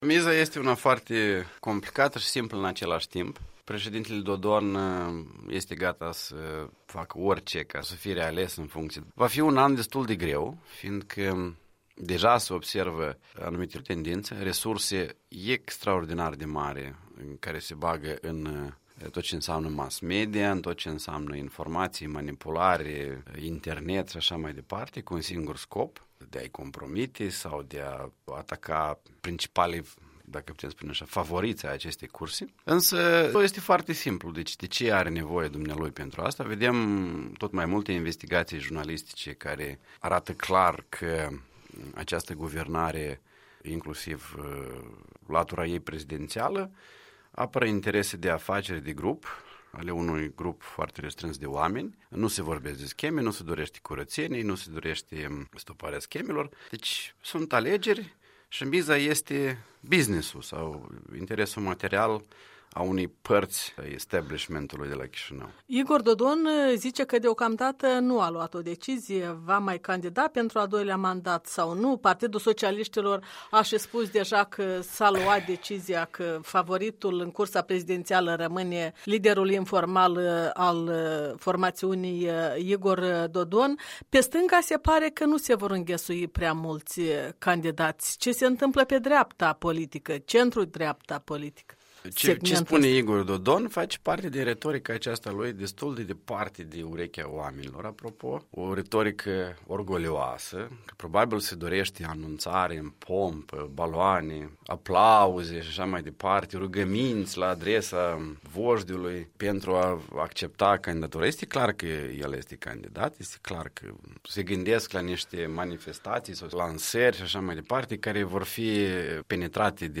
Interviul dimineții la EL: cu Vadim Pistrinciuc